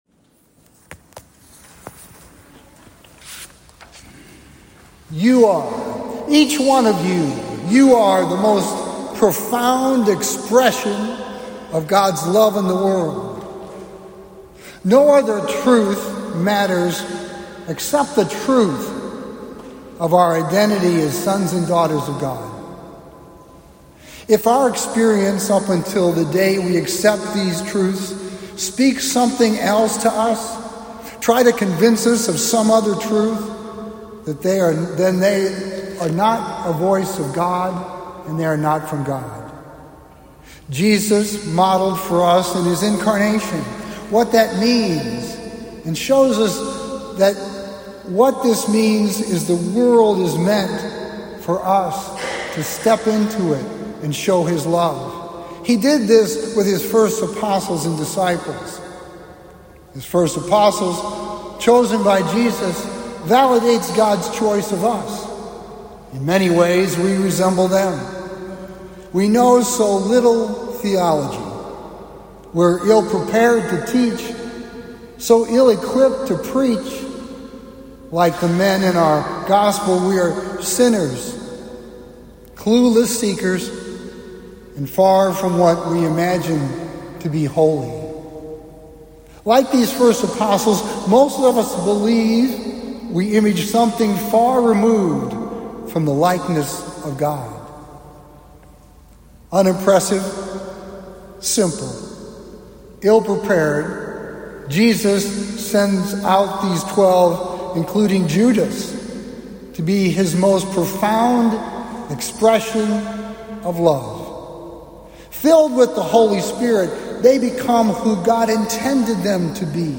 Homily – September 25, 2024